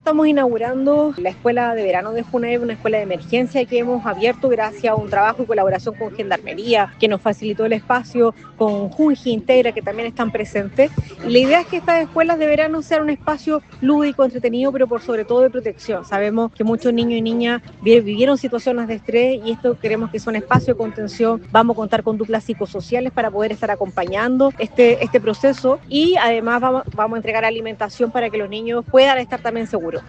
La Directora Nacional de Junaeb, Camila Rubio, explicó que el objetivo de la primera escuela de emergencia, es ser un espacio lúdico y entretenido, pero también de protección y contención.